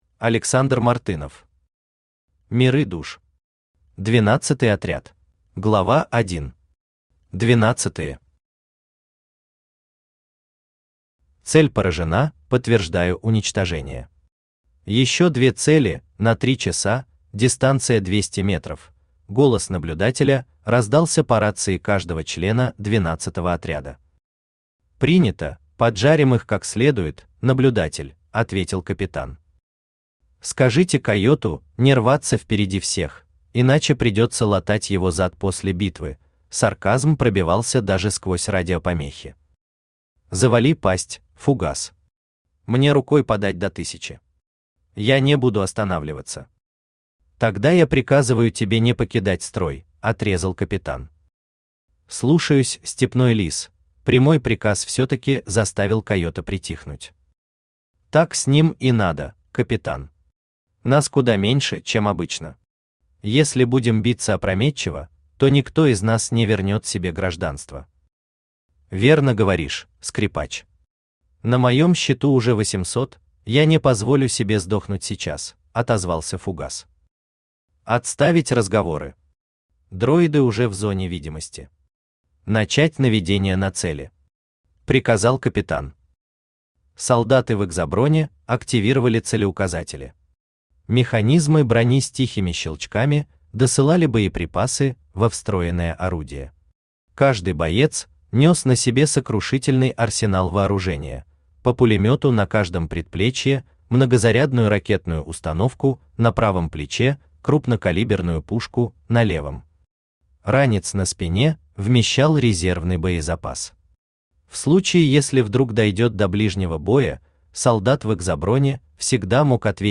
Аудиокнига Миры душ. Двенадцатый отряд | Библиотека аудиокниг
Двенадцатый отряд Автор Александр Мартынов Читает аудиокнигу Авточтец ЛитРес.